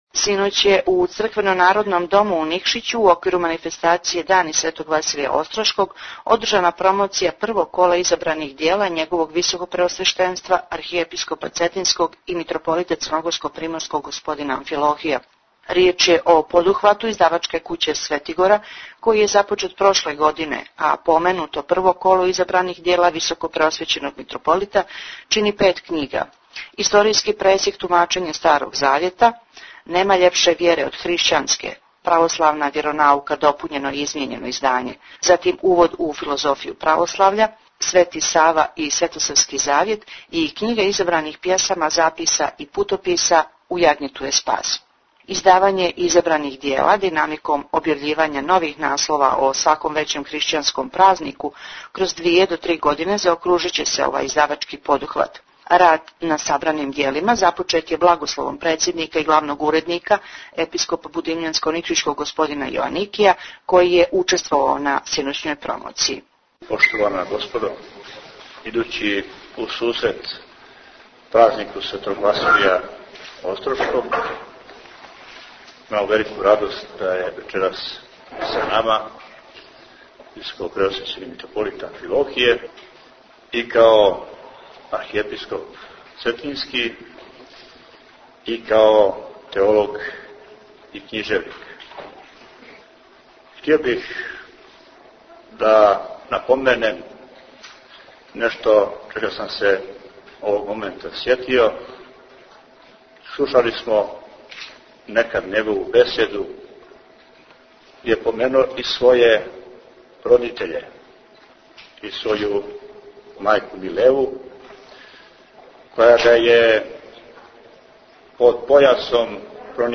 Промоција првог кола Изабраних дјела Архиепископа цетињског и Митрополита Црногорско-приморског Г. др Амфилохија Tagged: Извјештаји